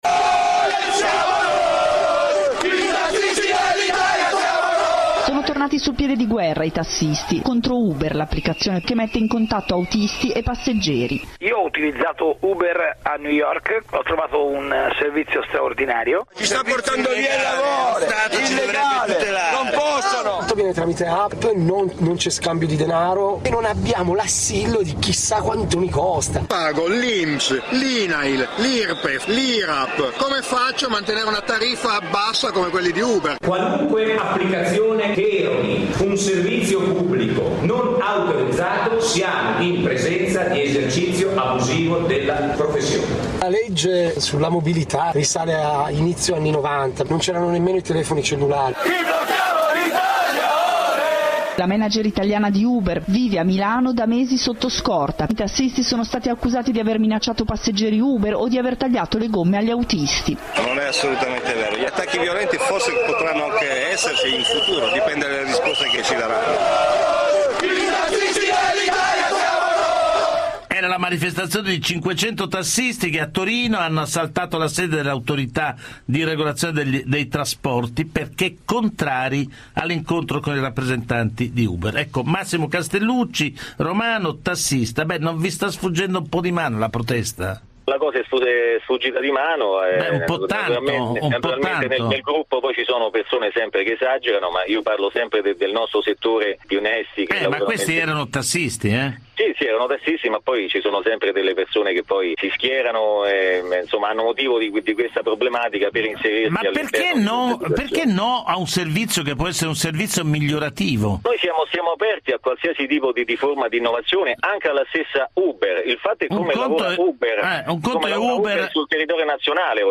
A MIX 24 puntata del 25 febbraio 2015, conduce Giovanni Minoli.